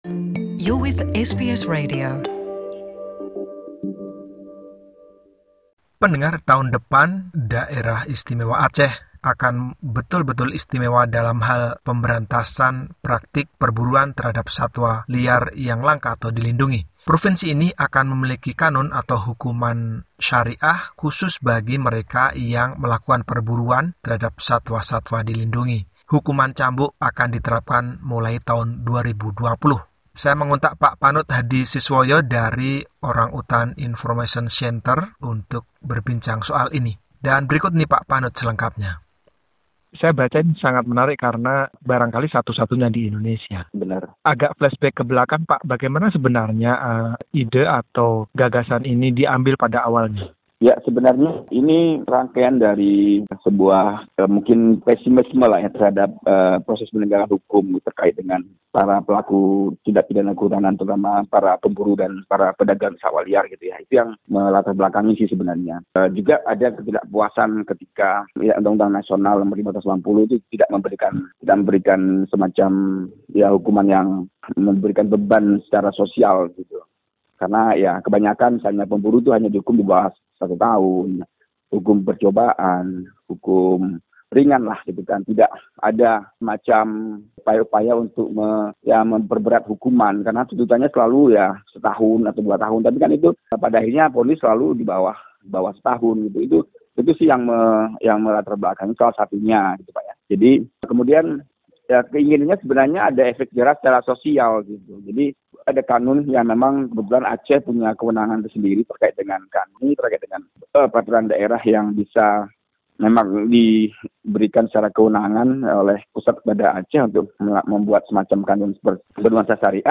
Wawancara
seorang konservasionis dari Sumatera mengenai hal ini.